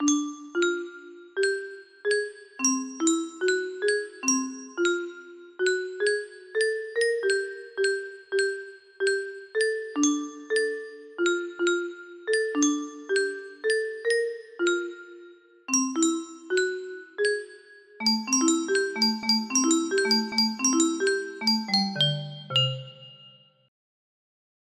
DOTSP - VHF music box melody